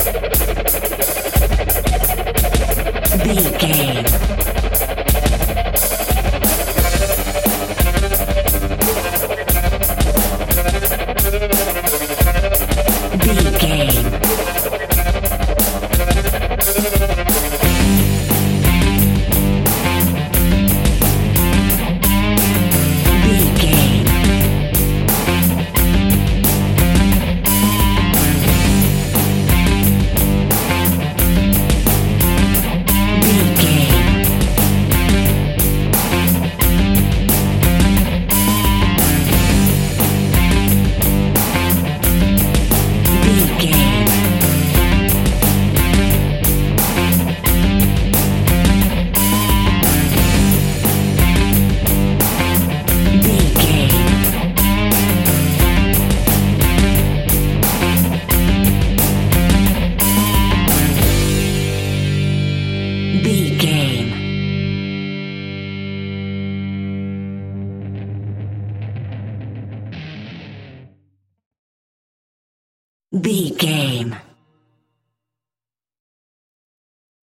Epic / Action
Aeolian/Minor
blues rock
instrumentals
Rock Bass
heavy drums
distorted guitars
hammond organ